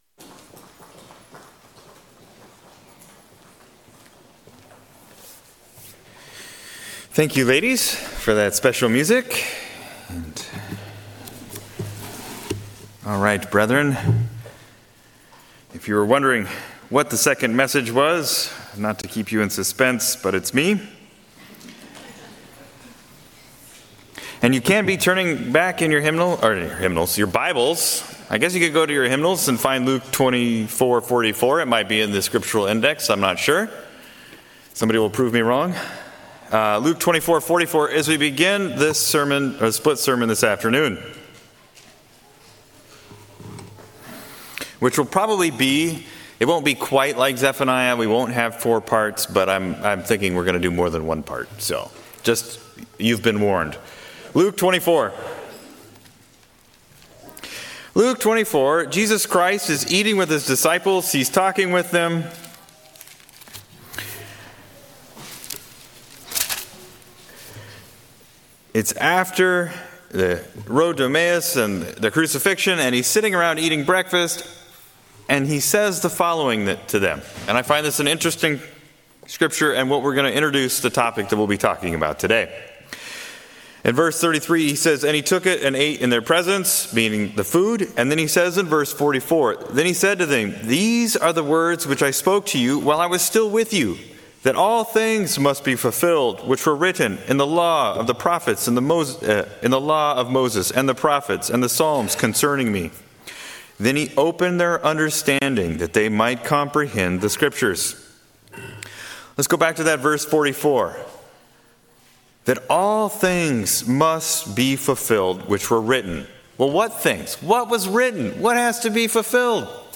Sermons
Given in Salem, OR